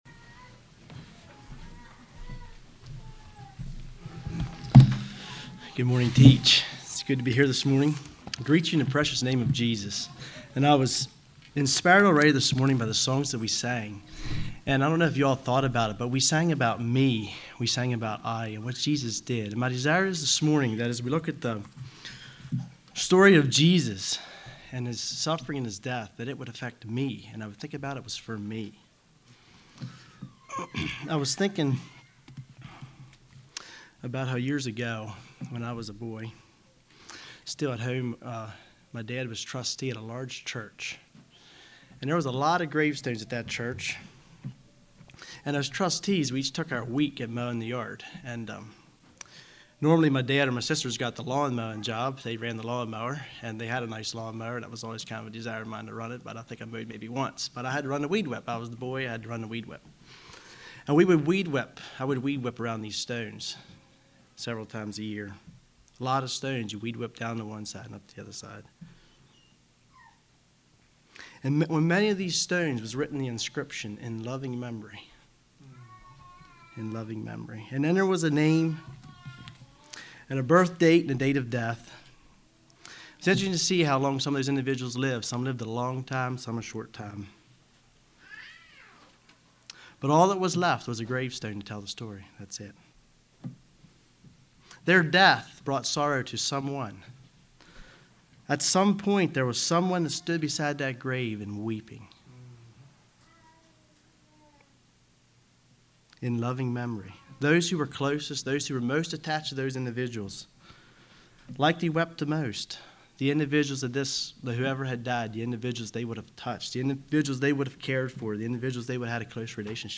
Sermons
Altoona | Bible Conference 2024